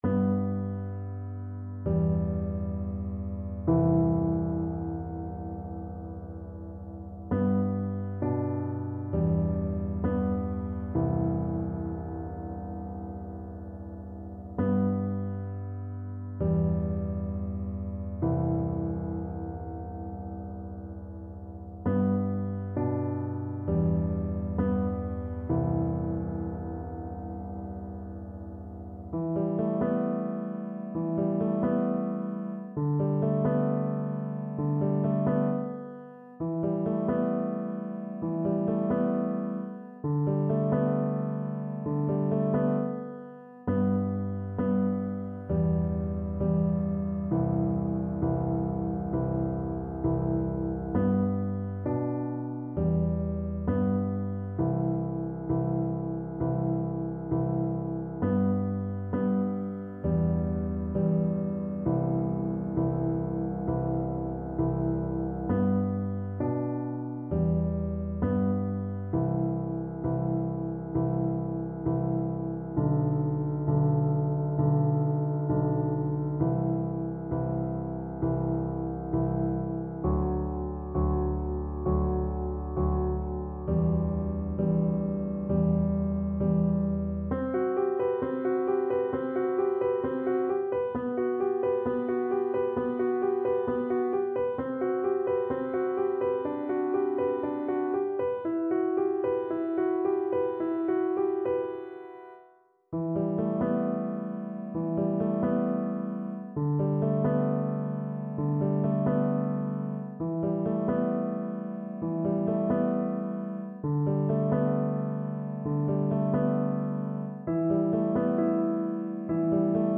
G4-E7
Classical (View more Classical Violin Music)